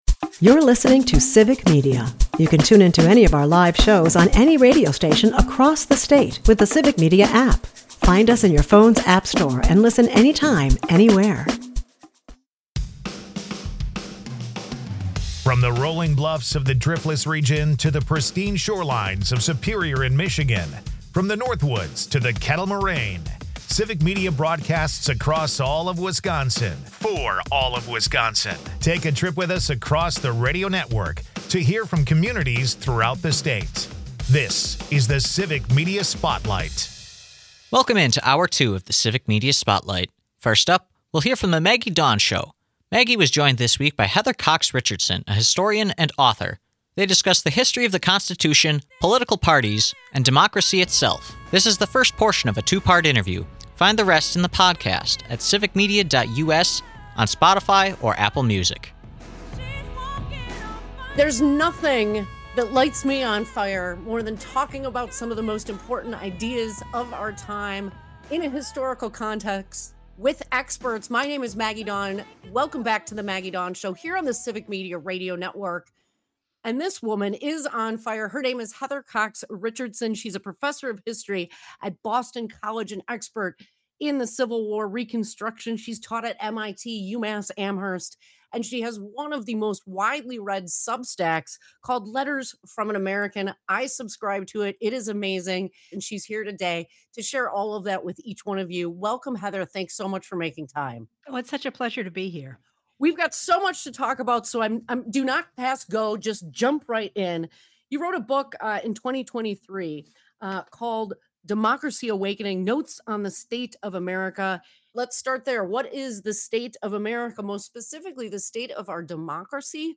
This is the first portion of a two part interview.